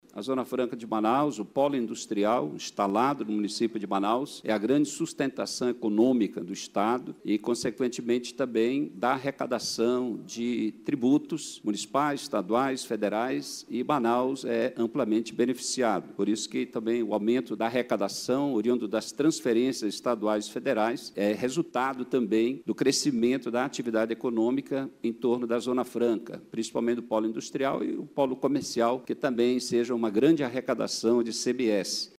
Ainda durante a sessão Plenária, o vereador José Ricardo, do PT, defendeu a Frente Parlamentar, no âmbito da Câmara Municipal, e ressaltou a importância do modelo ZFM, nos âmbitos econômico e fiscal.